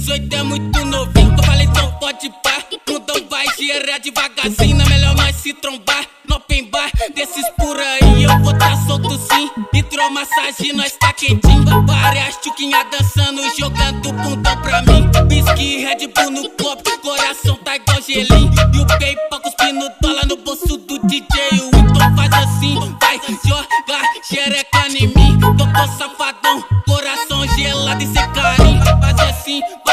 Жирный бас-клава и хлопковые гитары
Baile Funk Brazilian
Жанр: Фанк